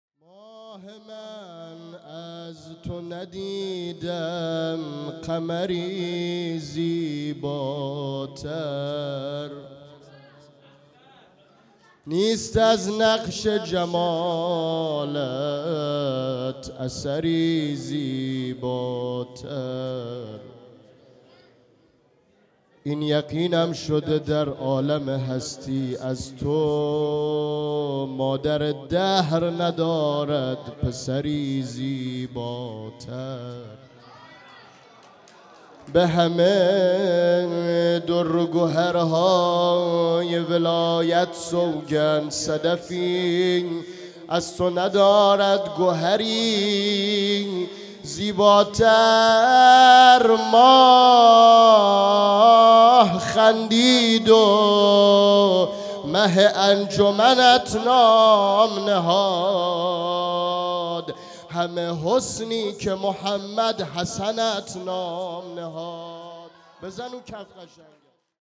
مدح ولادت امام حسن هیئت بیت العباس اهواز